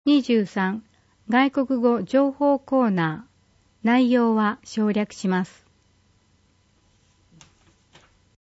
広報とうごう音訳版（2020年1月号）